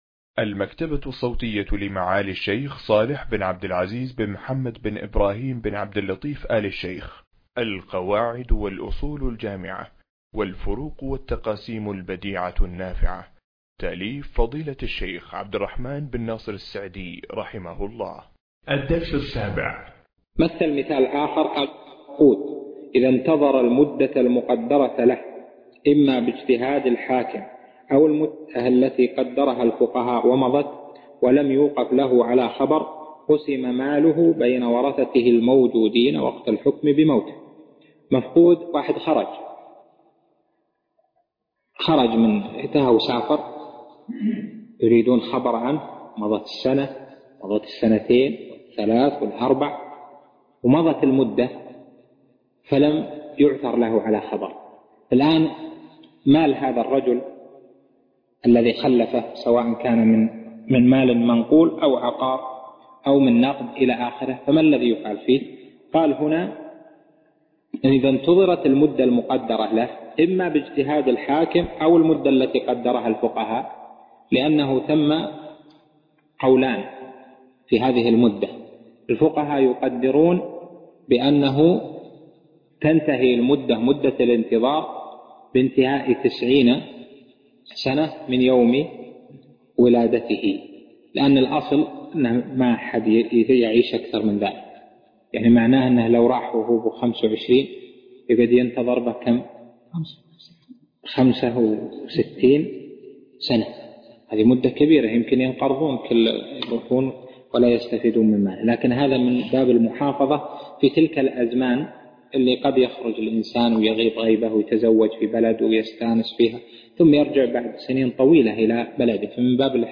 القواعد والأصول الجامعة والفروق والتقاسيم البديعة النافعة شرح الشيخ صالح بن عبد العزيز آل الشيخ الدرس 7